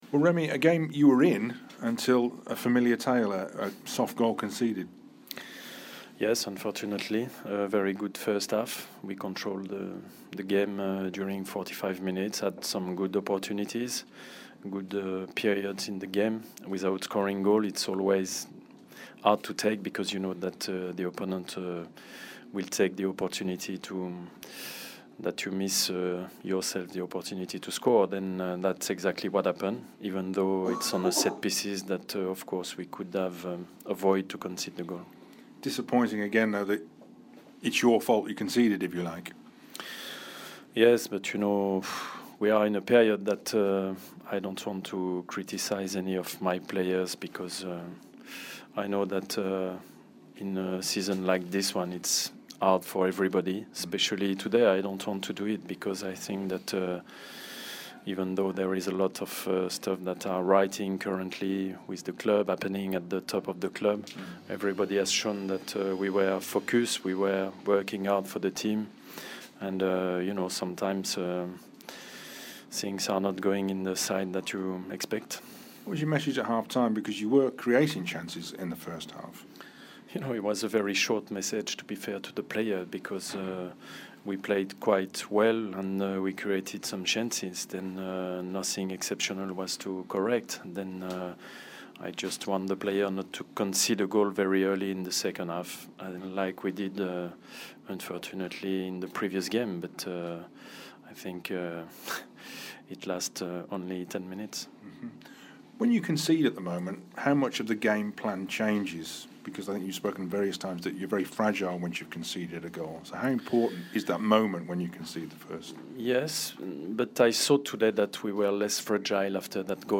The Aston Villa manager Remi Garde spoke to BBC WM after the 1-0 defeat at Swansea City.